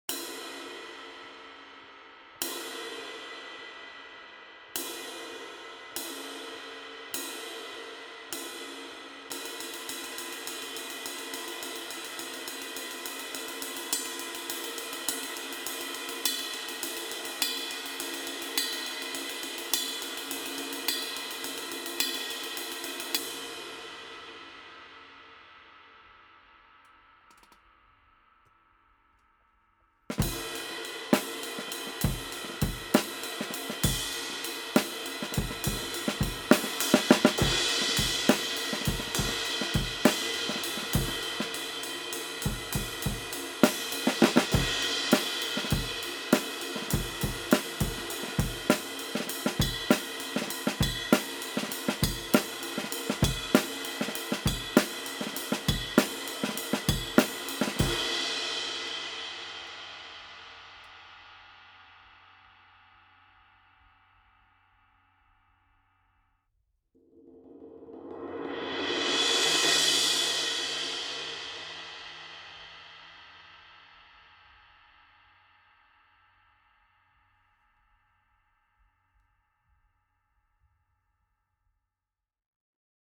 Hand-hammered from B20 bronze.